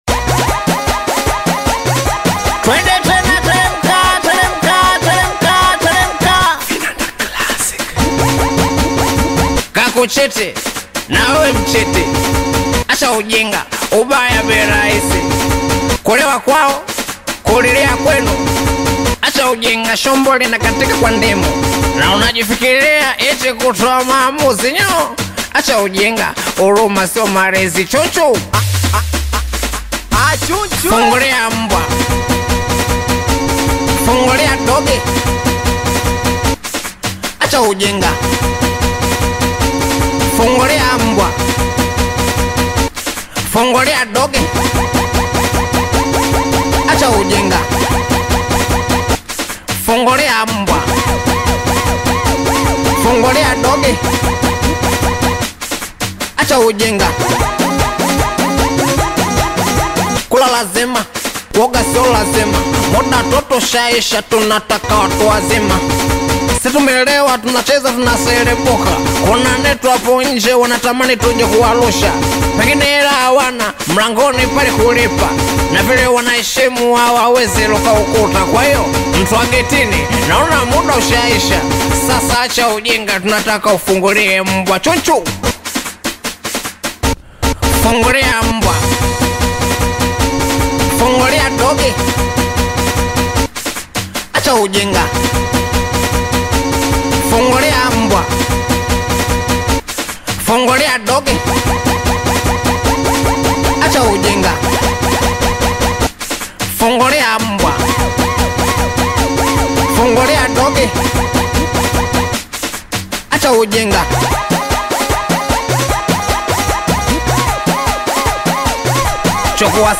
Tanzanian Bongo Flava Singeli